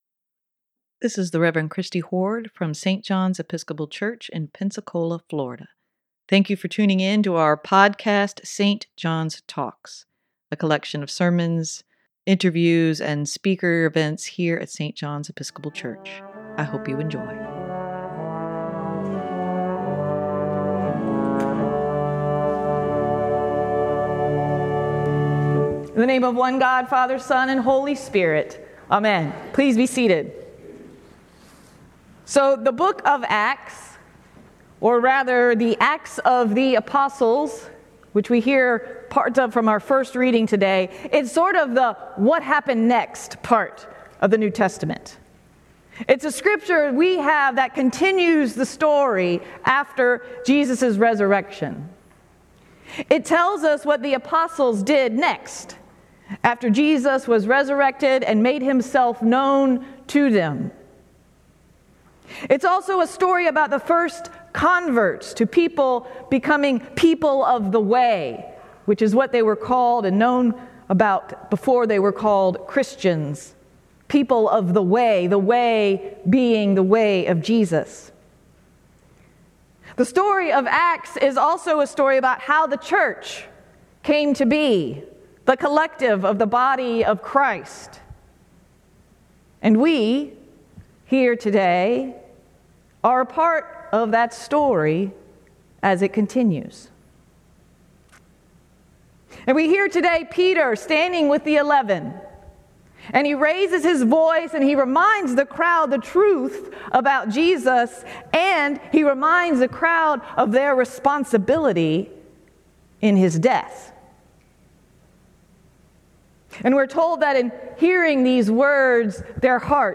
Sermon for April 23, 2023: Recognizing Jesus in the day-to-day moments - St. John's Episcopal Church